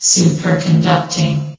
sound / vox_fem / superconducting.ogg
CitadelStationBot df15bbe0f0 [MIRROR] New & Fixed AI VOX Sound Files ( #6003 ) ...
superconducting.ogg